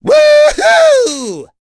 Ezekiel-vox-Happy4.wav